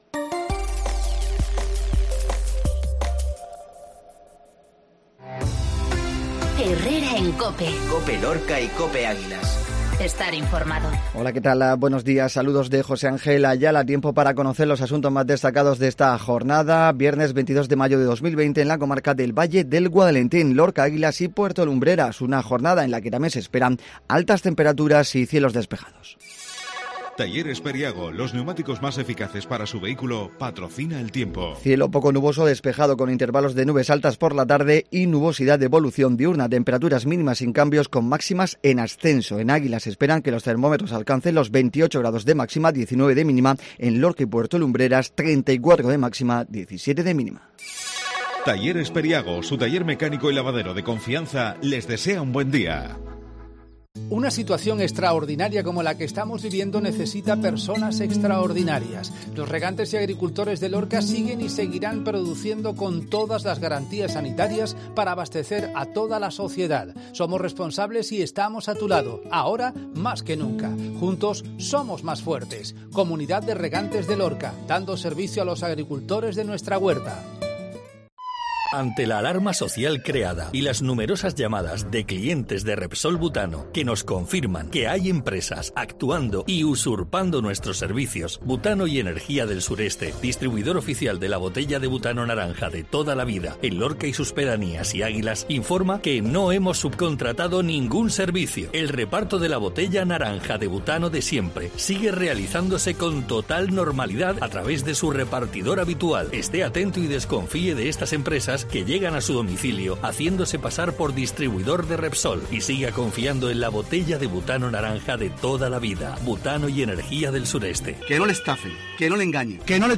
INFORMATIVO MATINAL VIERNES COPE